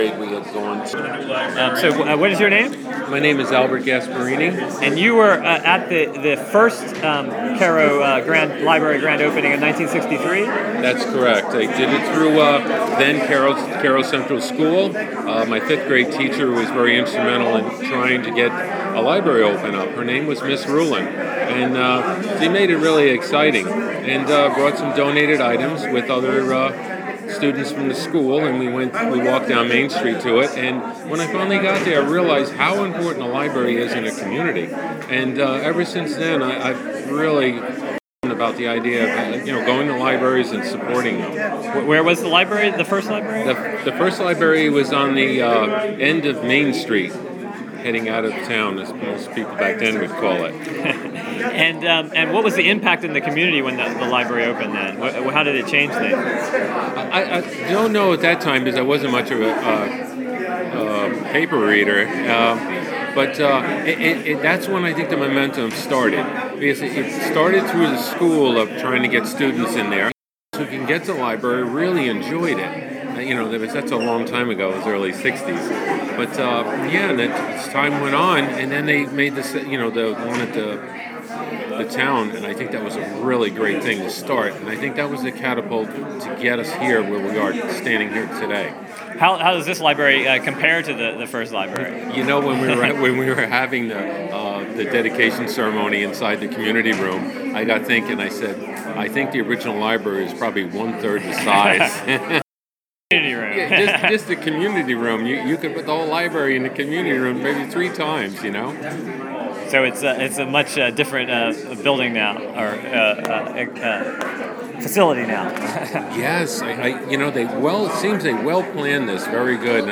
Recorded at Cairo Library grand opening.